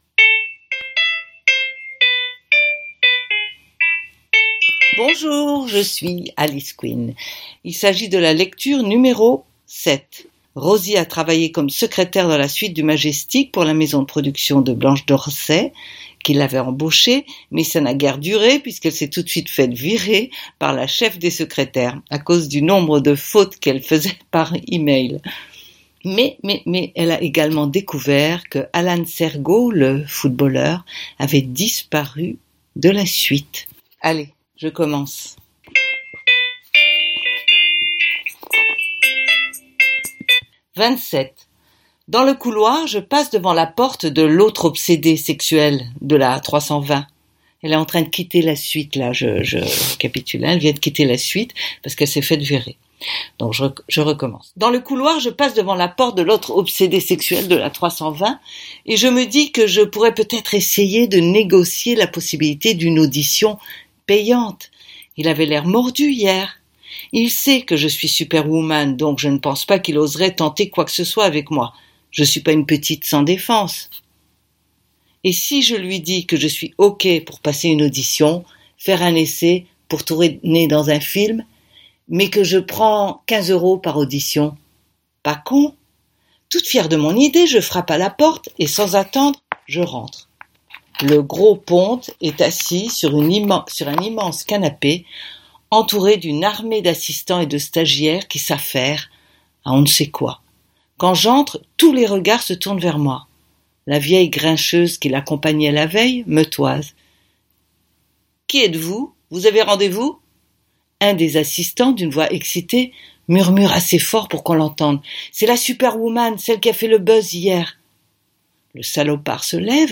Lecture #7 Ce roman fait partie de la série AU PAYS DE ROSIE MALDONNE.